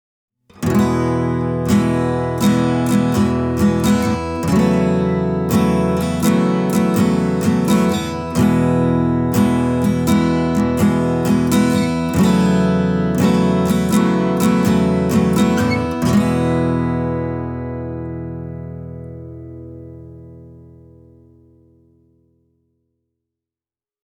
The Pickat Artisan adds a charming warm bottom end to the picture, without the sound turning mushy. The trebles are clean and clear, but also smooth as silk.
Here are three audio clips played on a cedar-topped jumbo (a Takamine N-20) and recorded with a pair of Røde M5 condenser mics:
The Pickat Artisan will add roundness, depth and a charming top end sheen to your tone.
takamine-n-20-pickat-plectrum-rocc88de-m5.mp3